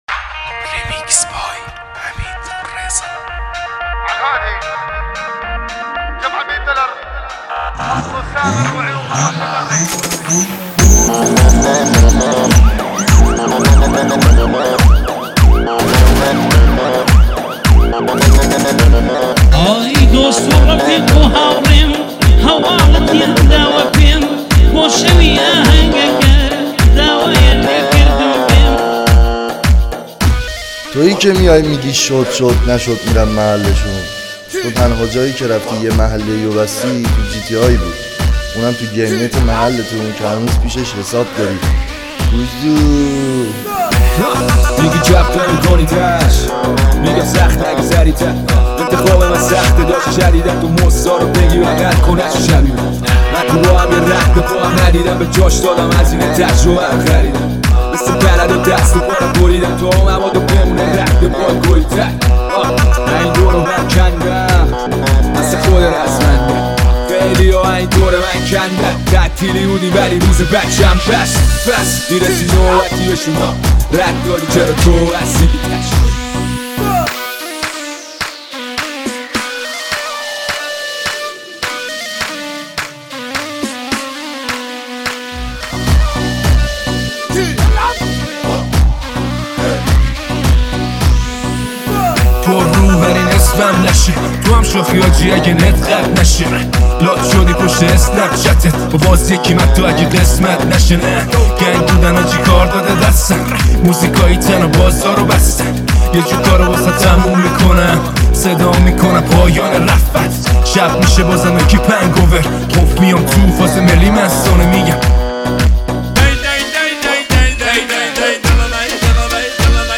دانلود اهنگ به فرزندان خود قاچاق یاد بدید ریمیکس عربی شوتی